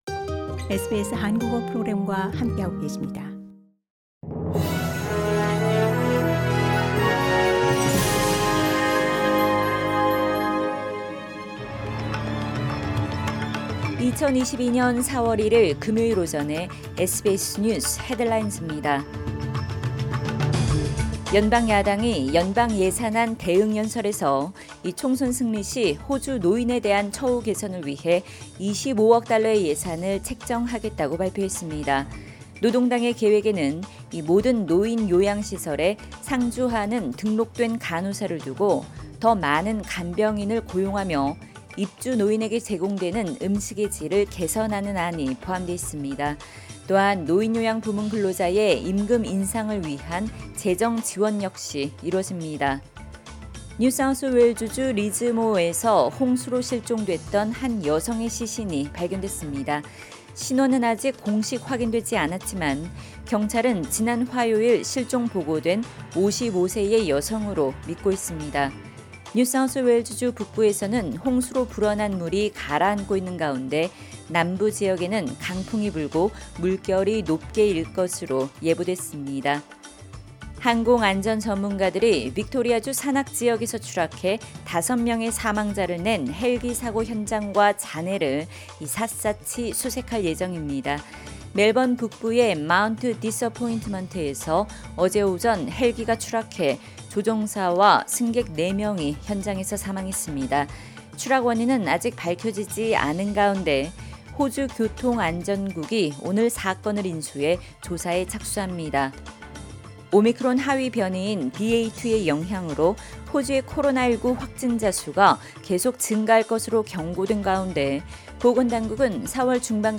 2022년 4월 1일 금요일 오전 SBS 뉴스 헤드라인즈입니다.